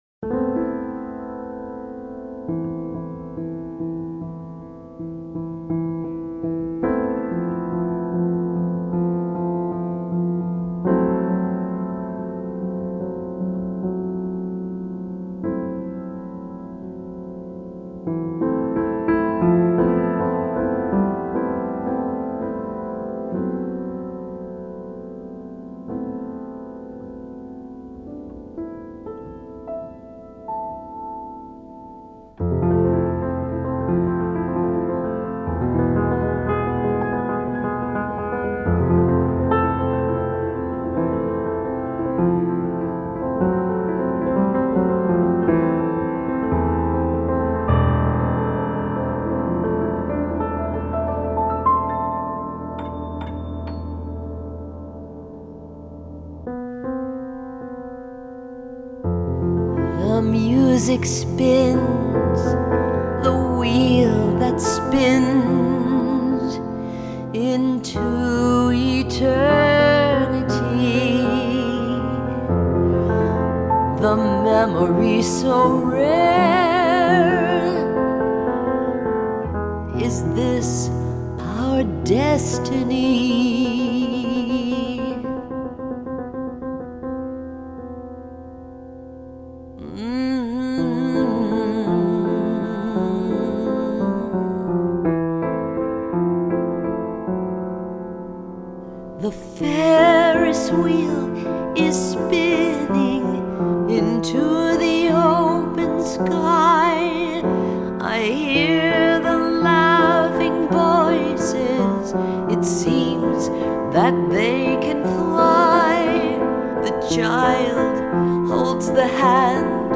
pianist-composer
FILE: Jazz Vox